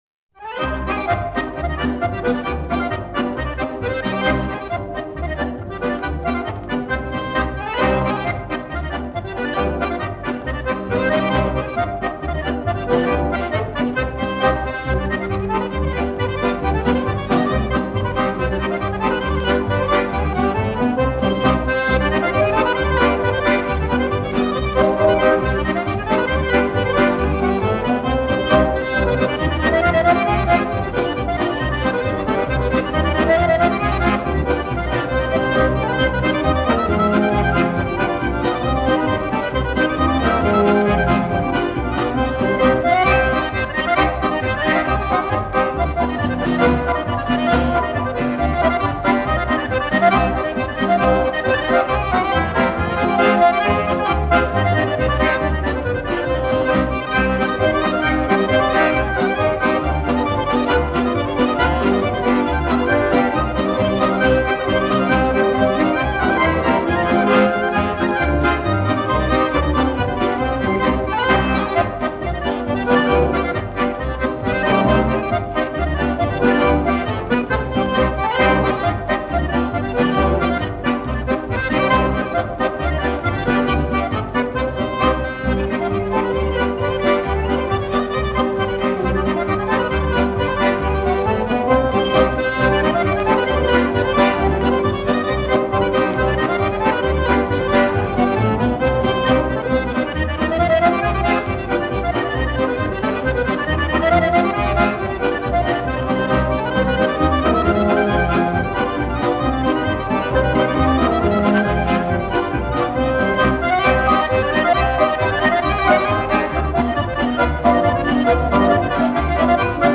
Der Akkordeonspieler Vili Vesterinen nahm im Jahre 1939 zusammen mit dem Akkordeonorchester Dallape das Stück "Die Polka von Säkkijärvi" (